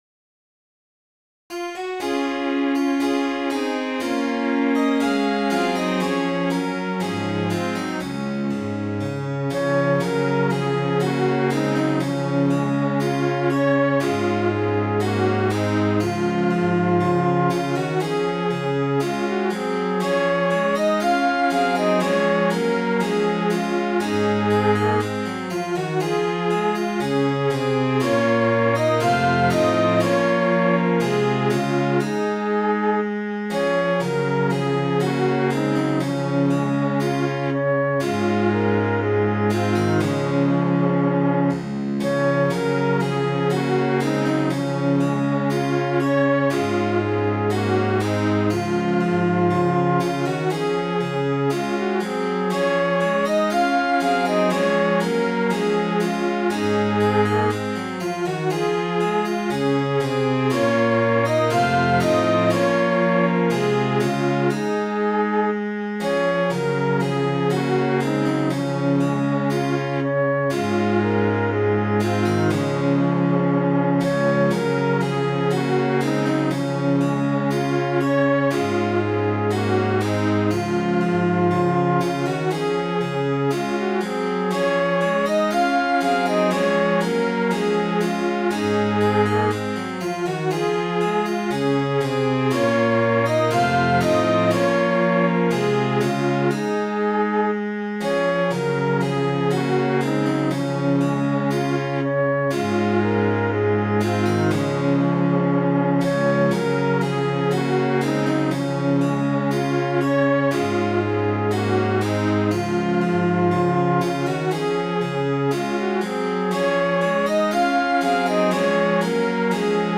belfast.mid.ogg